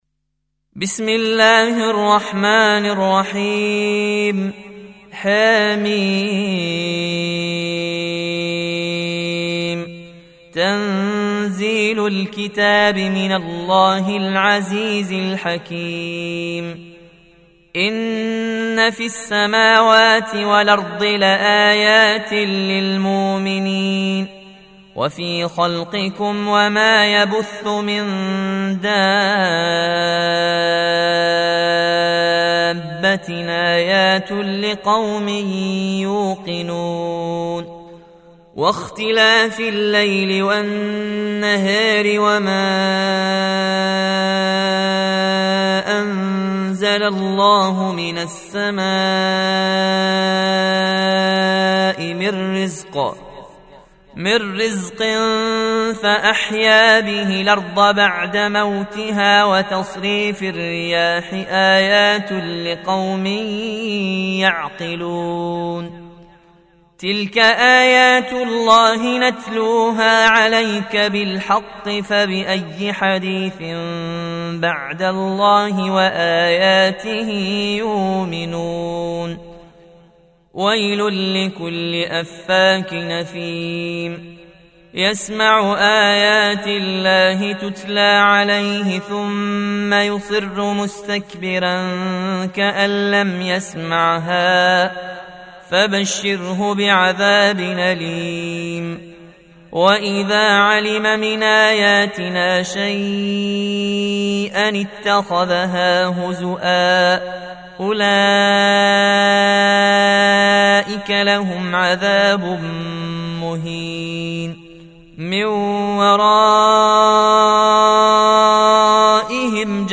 Audio Quran Tarteel Recitation
Surah Sequence تتابع السورة Download Surah حمّل السورة Reciting Murattalah Audio for 45. Surah Al-J�thiya سورة الجاثية N.B *Surah Includes Al-Basmalah Reciters Sequents تتابع التلاوات Reciters Repeats تكرار التلاوات